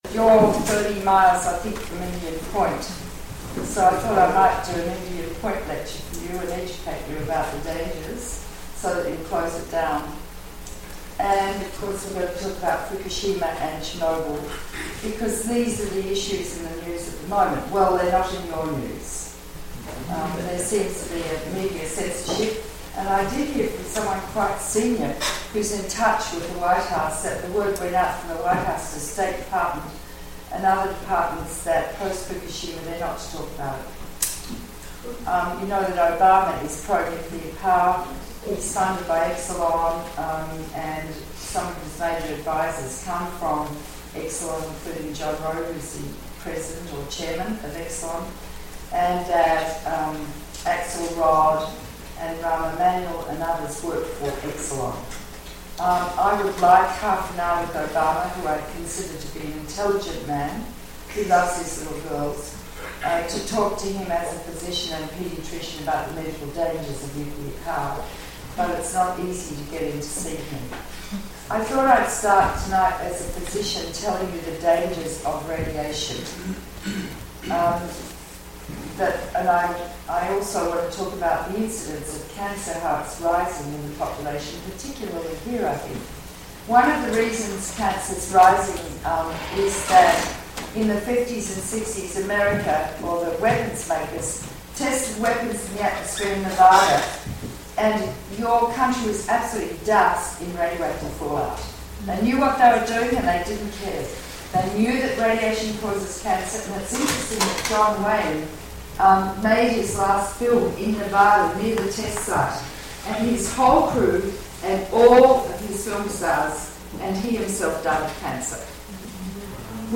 Edited talk at Time and Space Limited in Hudson.